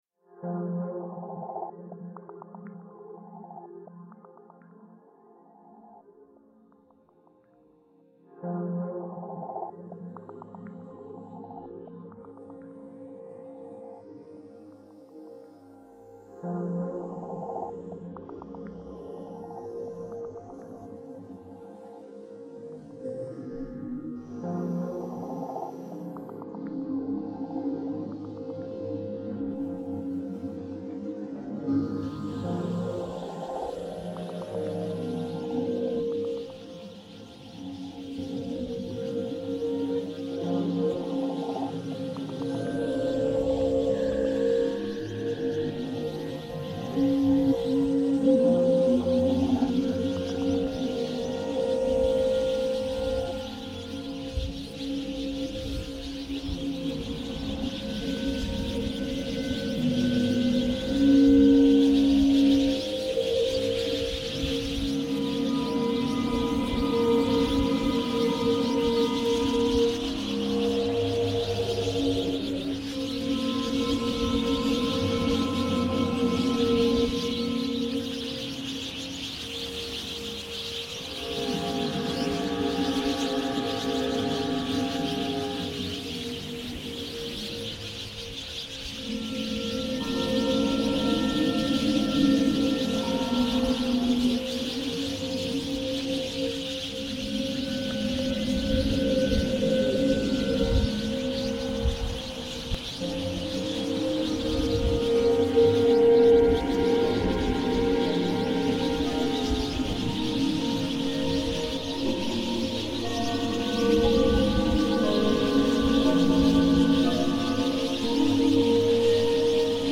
Chios birdsong reimagined